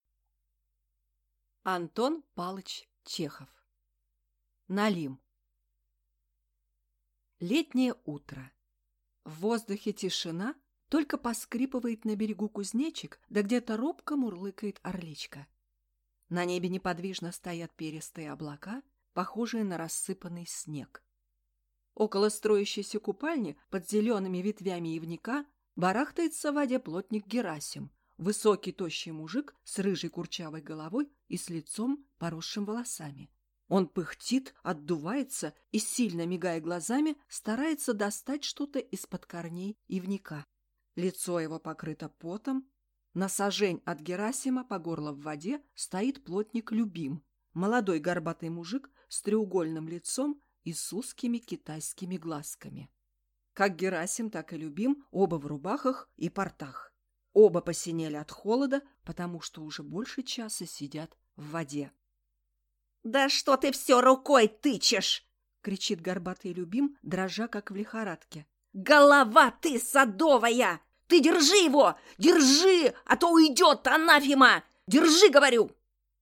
Aудиокнига Налим Автор Антон Чехов